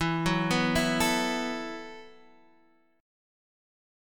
Esus2sus4 chord